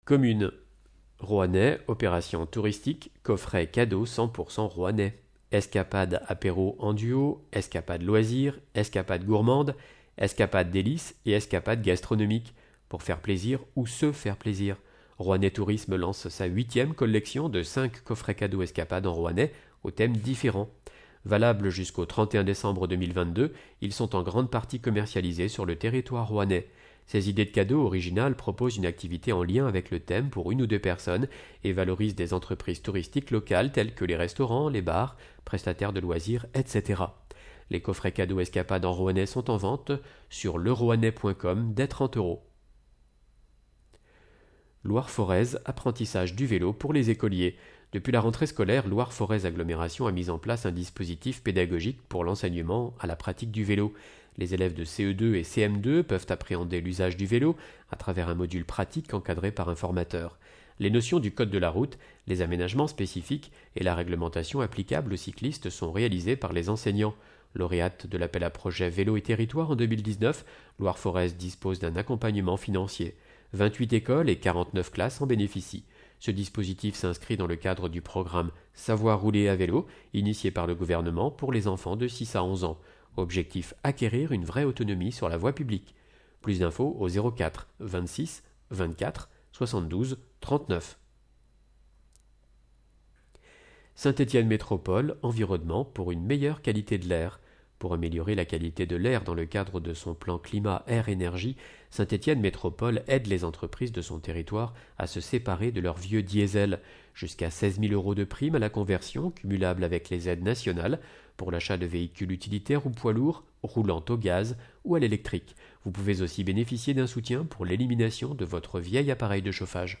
Loire Magazine n°144 version sonore